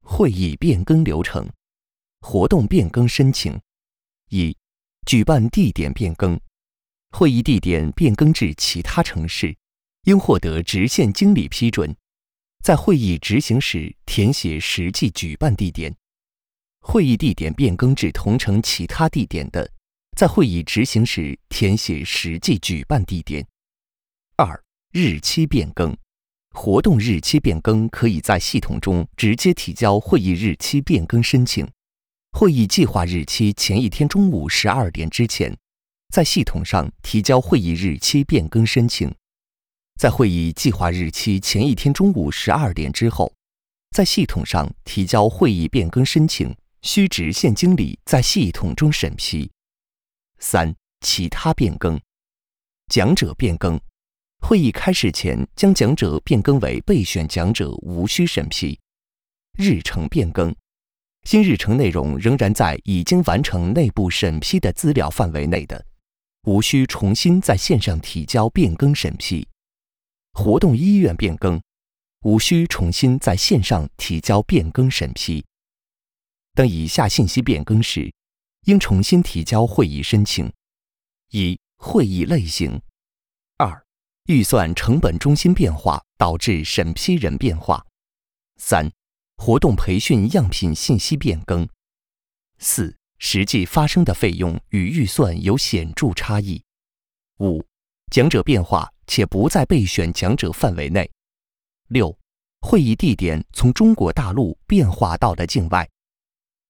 Chinese_Male_005VoiceArtist_20Hours_High_Quality_Voice_Dataset